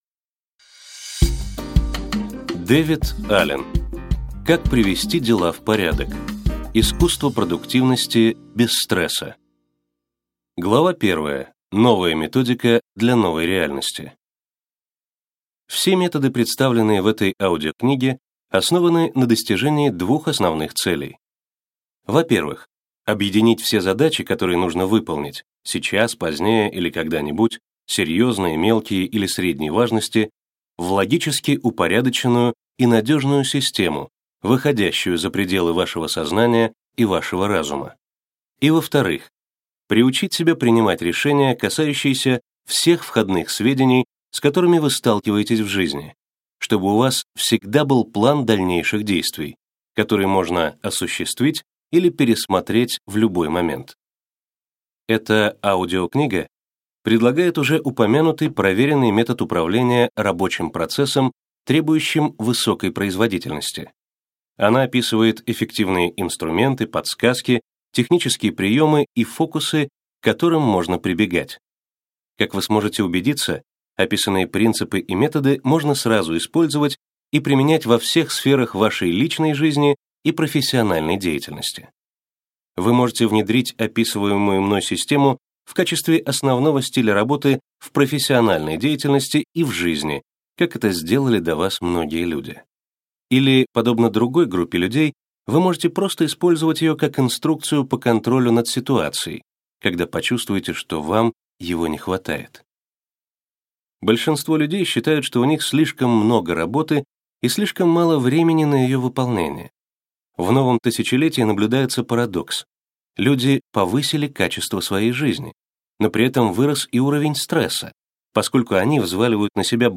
Аудиокнига Как привести дела в порядок. Искусство продуктивности без стресса | Библиотека аудиокниг